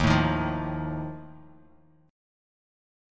Listen to Ebsus2b5 strummed